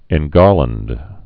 (ĕn-gärlənd)